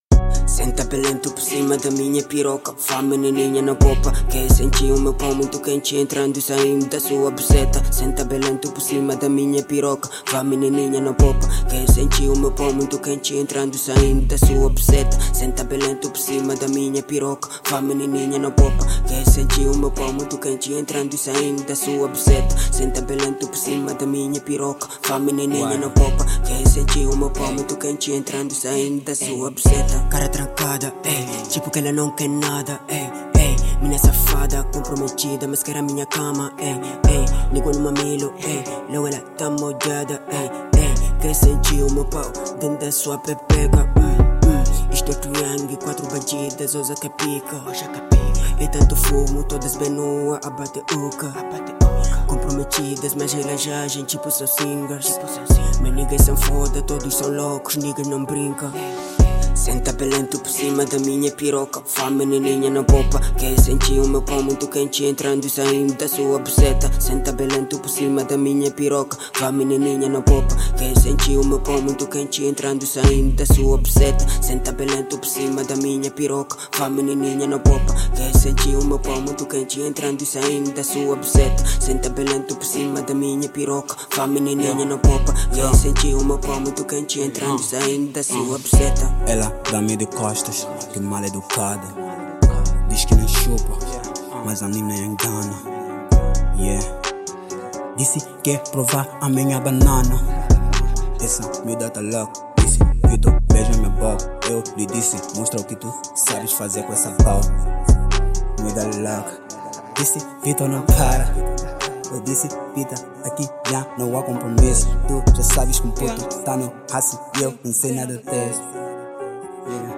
Genero: Drill